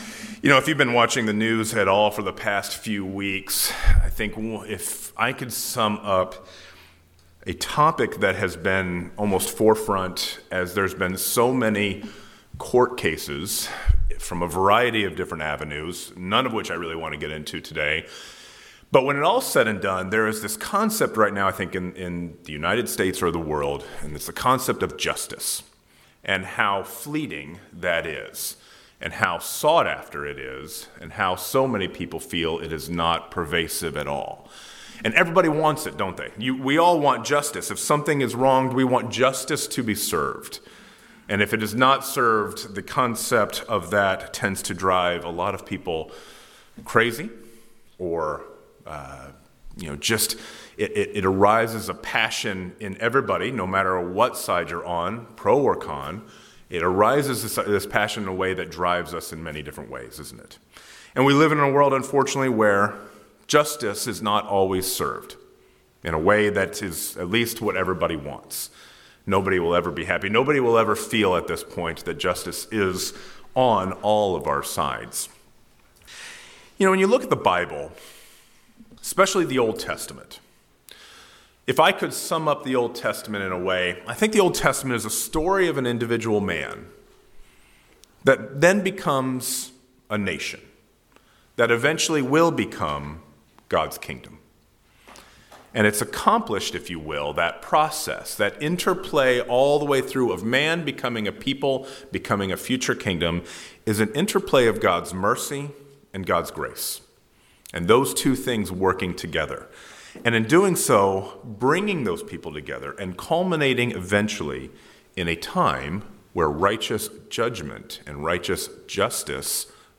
The need for justice is a topic that is covered a lot in our world today. This sermon takes a look at how God served justice to both people and a nation while using grace and mercy, leaving us an example to follow.